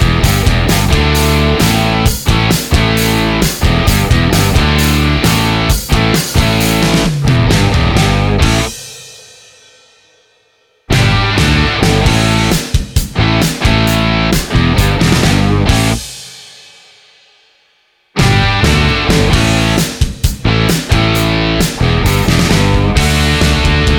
no Backing Vocals Rock 4:00 Buy £1.50